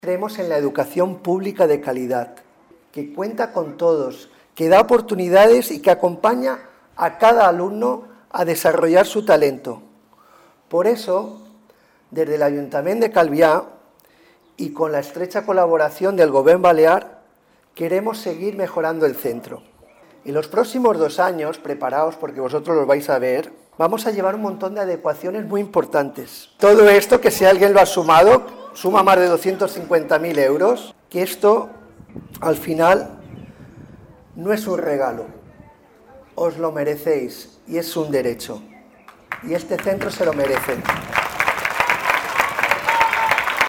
mayors-statements-ceip-sa-morisca.mp3